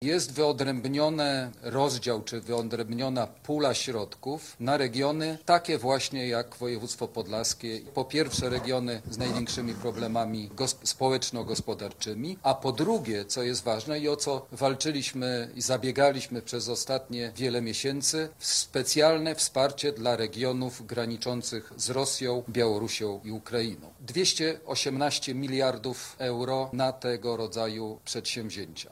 218 mld euro kwota wyodrębniona na regiony przygraniczne i ich bezpieczeństwo o czym mówił Jacek Protas Poseł do Parlamentu Europejskiego.